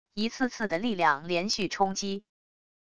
一次次的力量连续冲击wav音频